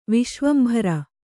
♪ viśvambhara